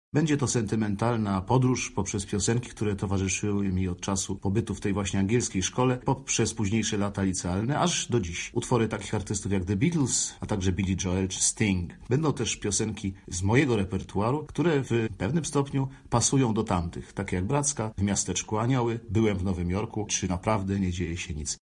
O repertuarze mówi Grzegorz Turnau.